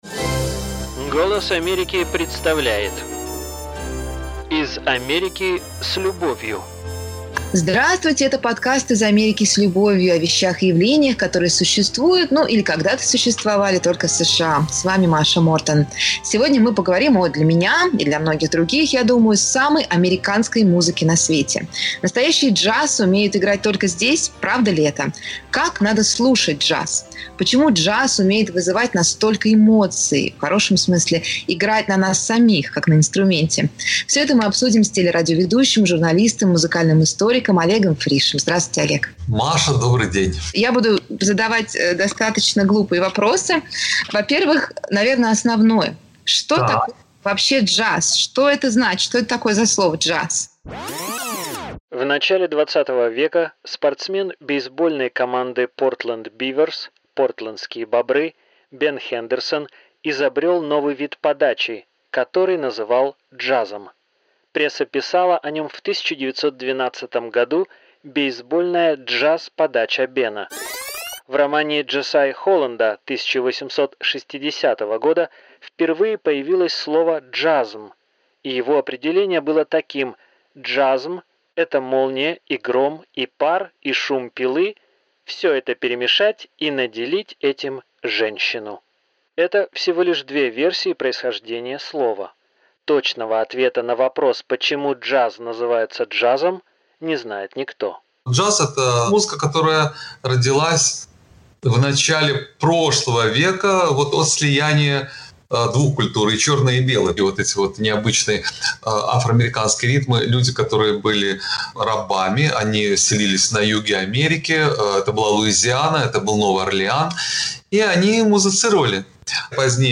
Слушайте джаз вместе с нами